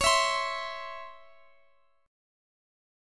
Listen to DbmM7 strummed